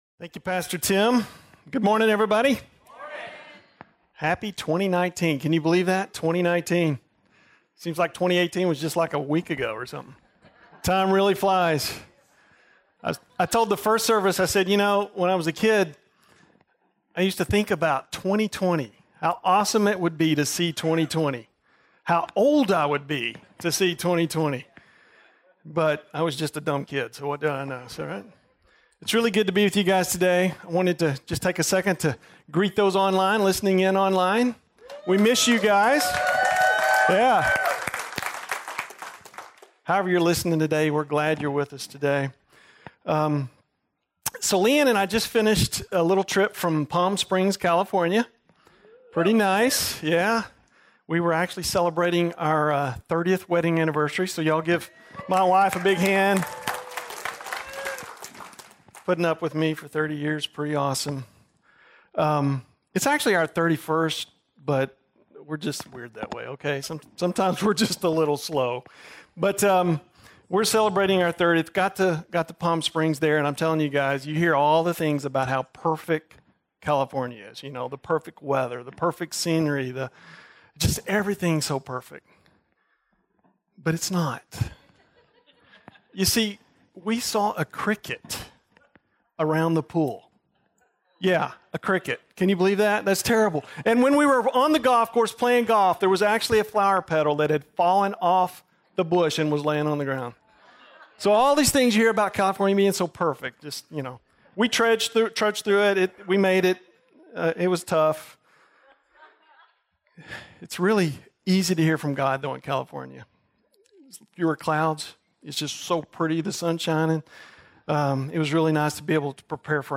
2019 Sermon